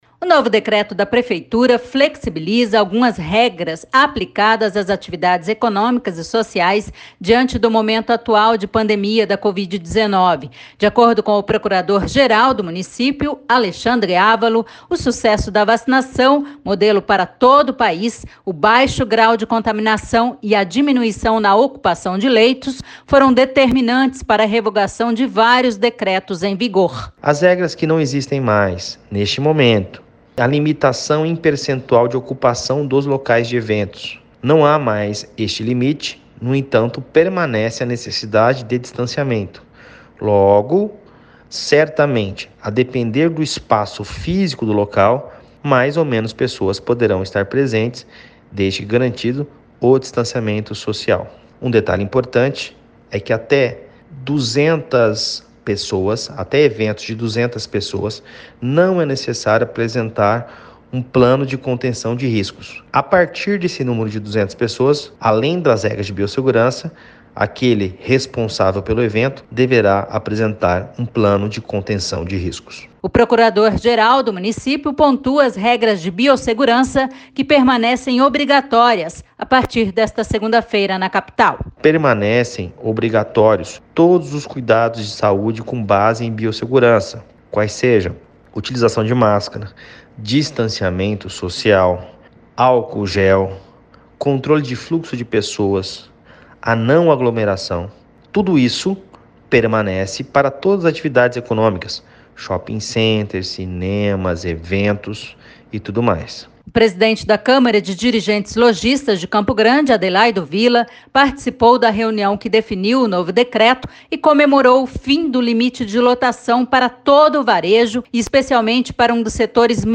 O procurador-geral do município pontua as regras de biossegurança que permanecem obrigatórias, a partir desta segunda feira na capital.